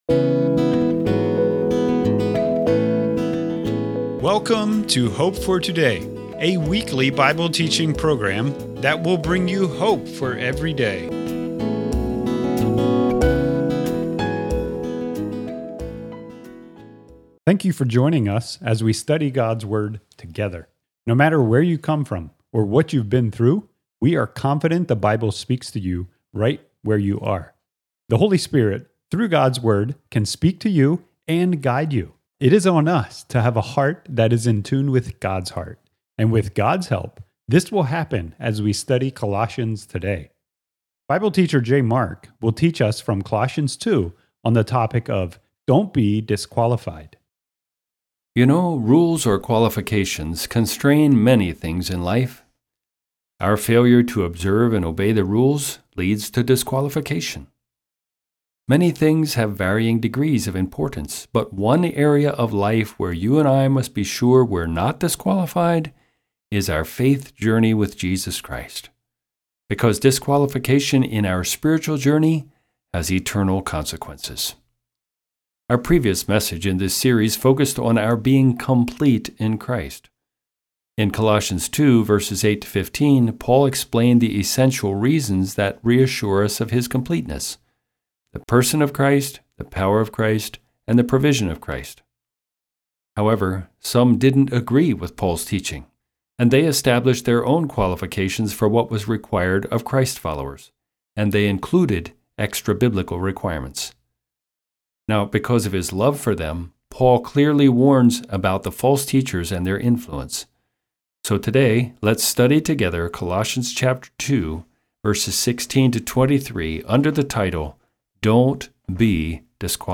Colossians 2:16-23 Thank you for joining us as we study God’s Word together.